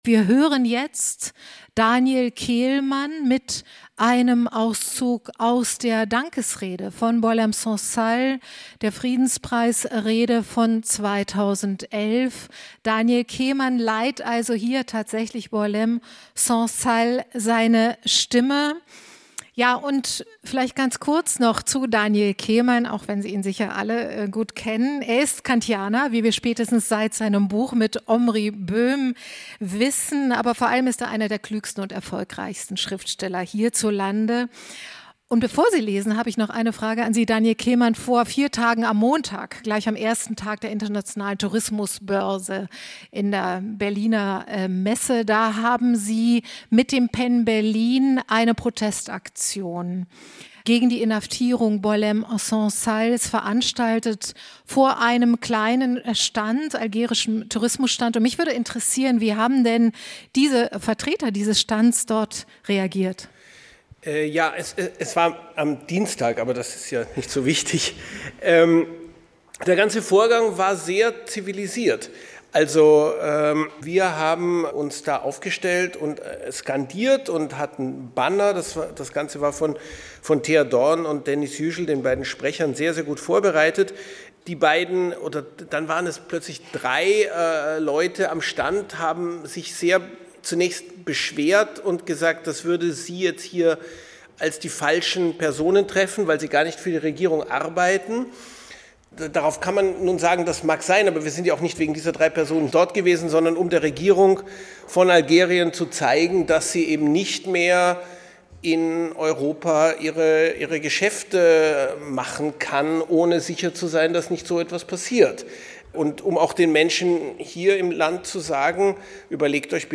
Radio 3, Aufzeichnung der Solidaritätsveranstaltung mit Herta Müller, Irina Scherbakowa, Daniel Kehlmann, Liao Yiwu, Kamel Daoud u.a.